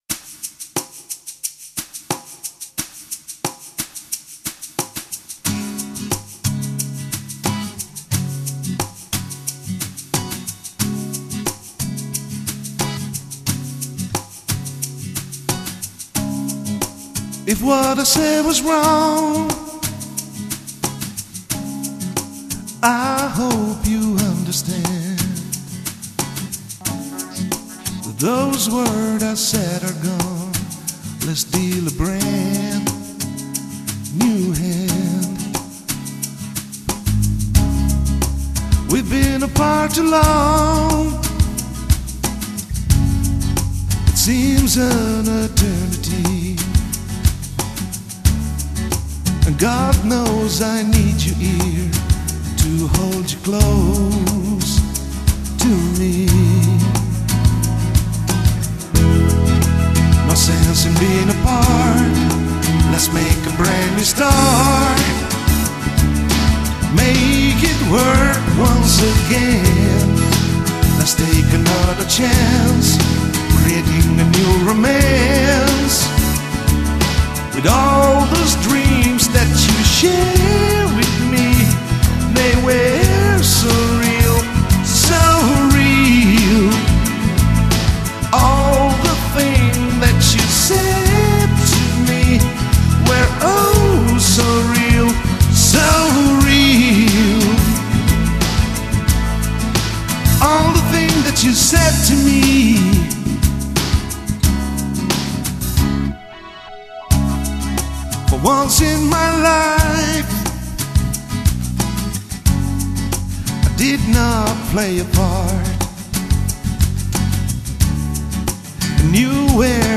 Vocals - Bass - Acoustic Guitar
Electric Guitar - Keyboards and Drums Programming
Electric Guitar